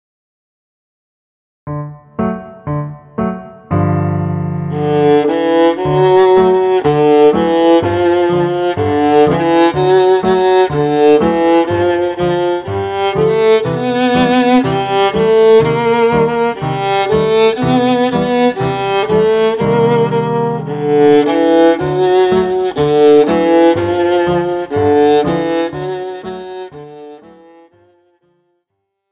Besetzung: Instrumentalnoten für Viola